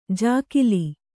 ♪ jākili